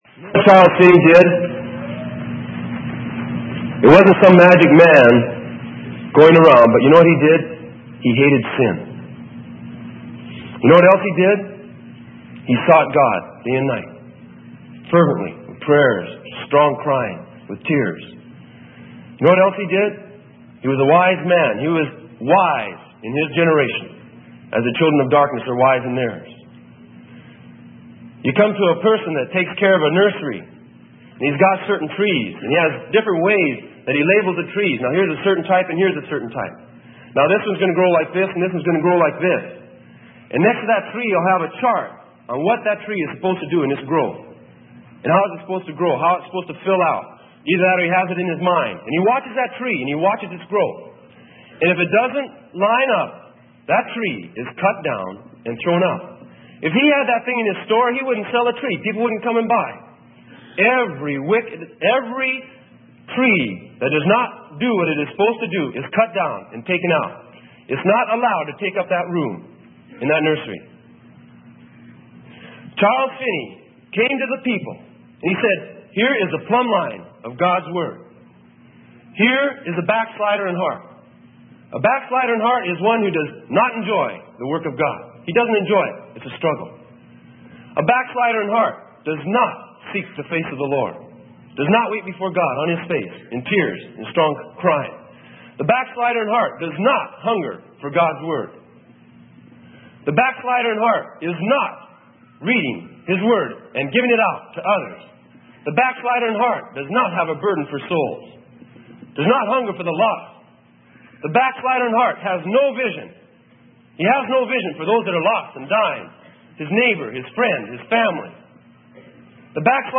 Sermon: Profit - Seeking the Lord Himself - Freely Given Online Library